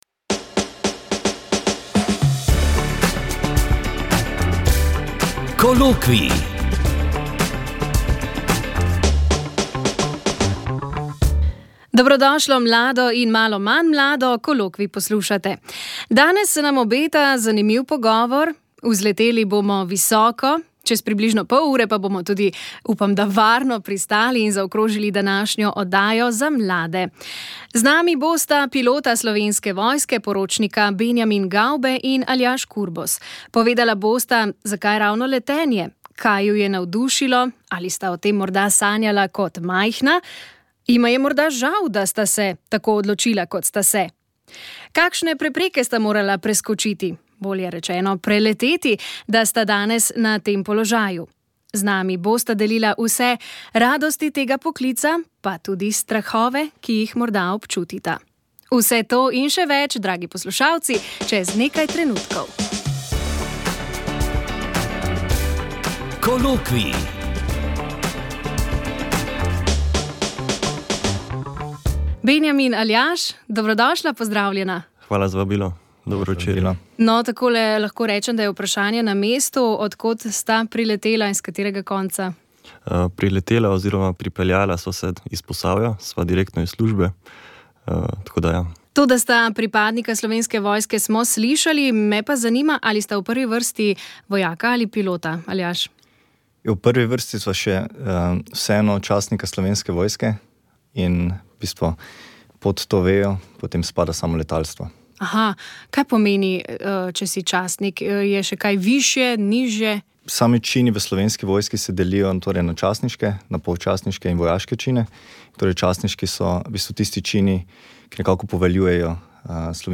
Najprej pa se z okoliščinami srečajo gorski reševalci, ki umrlega odnesejo v dolino. V posebni praznični izdaji Doživetij narave ob 17. uri boste slišali nekaj razmišljanj o smrti, spregovorila bosta tudi alpinista Štremfelj. Srečali pa se bomo tudi z življenjsko zgodbo blaženega Pier Giorgia Frassati-ija, ki je bil navdušen planinec.